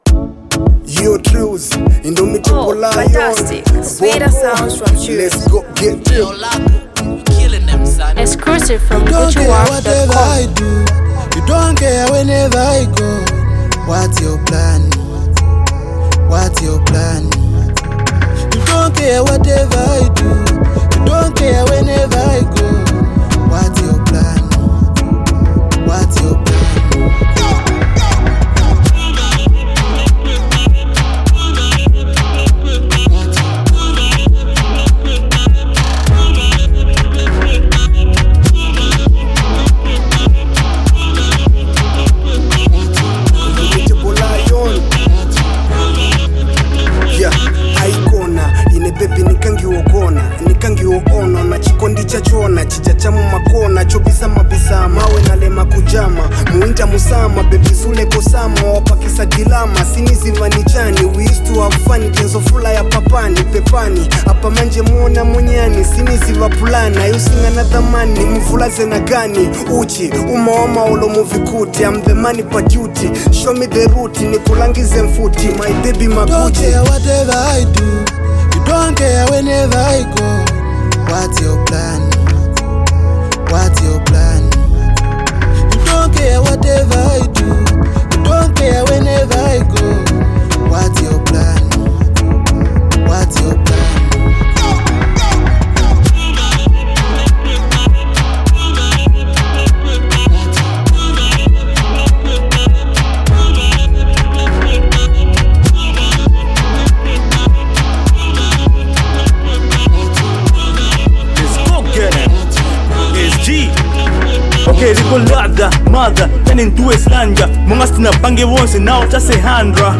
a hardcore rapper from the Eastern region
high-energy collaboration